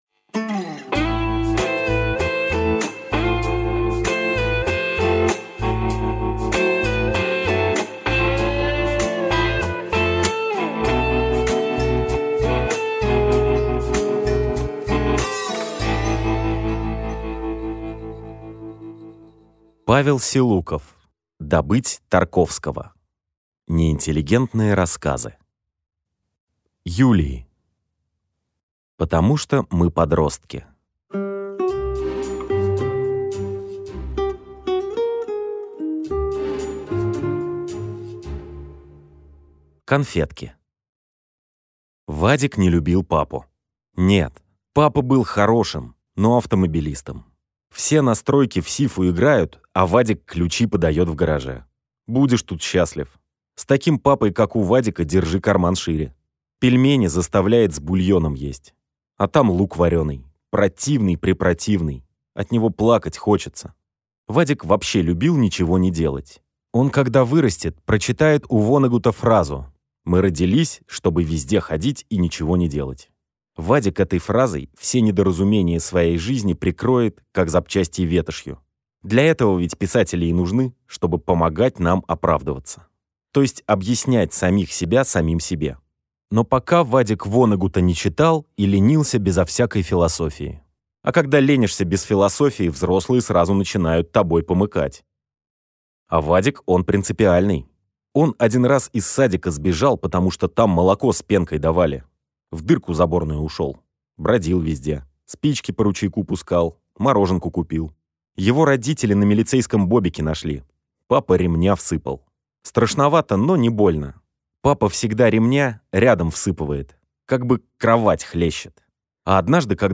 Аудиокнига Добыть Тарковского. Неинтеллигентные рассказы | Библиотека аудиокниг